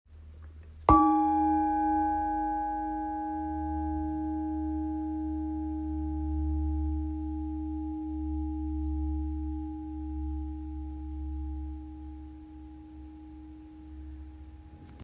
Traditional Thadobati Bowl 13cm – 18cm
Handcrafted by skilled artisans, each bowl is designed for both striking and singing, producing clear, harmonious sounds with a wide range of tones, long-lasting vibrations, and stable overtones.